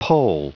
Prononciation du mot pole en anglais (fichier audio)
Prononciation du mot : pole